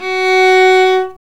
Index of /90_sSampleCDs/Roland L-CD702/VOL-1/STR_Viola Solo/STR_Vla3 _ marc
STR VIOLA 09.wav